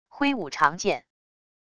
挥舞长剑wav音频